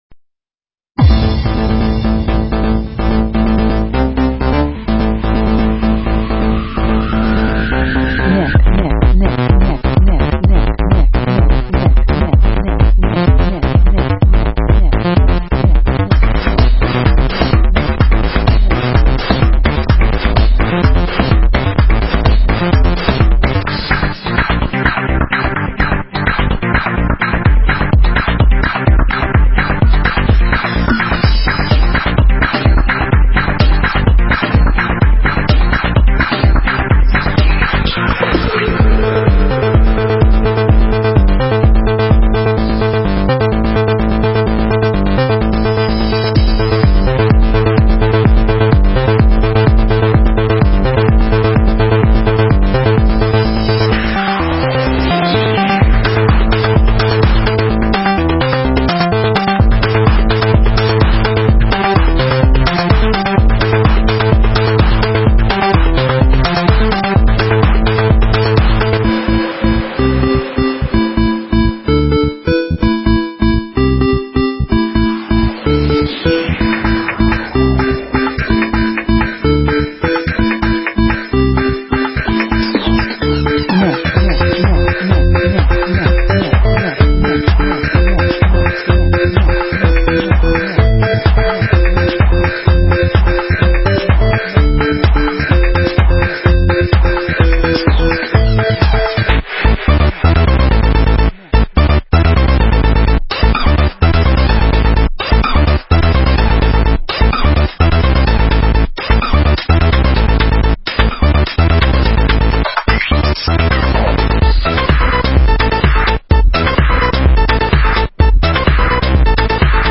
Dj Mix Hindi Song [1000 ]